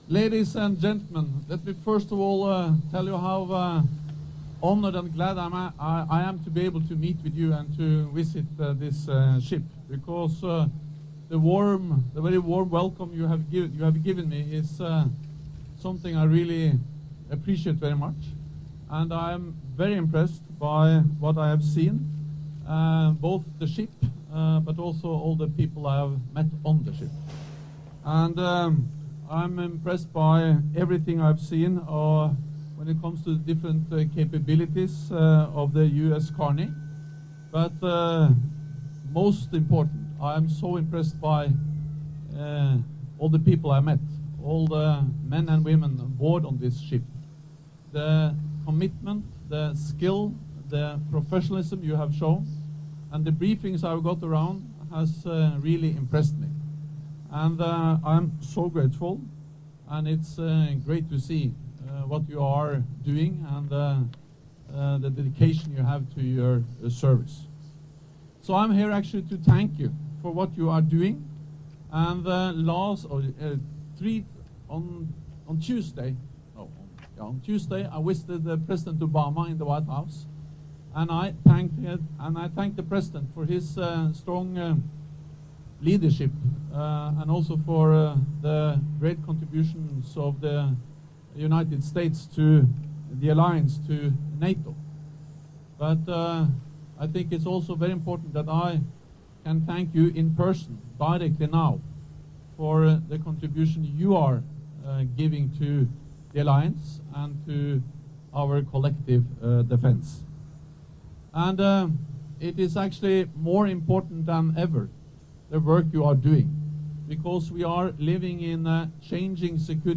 Secretary General Jens Stoltenberg commended the key US contribution to NATO's missile defence capability in a visit to the USS Carney, an Aegis destroyer, at Naval Station Mayport in Florida on Friday (29 May 2015). The destroyer will set sail to Rota, Spain, in September to be part of the Alliance’s ballistic missile defence system alongside three sister ships.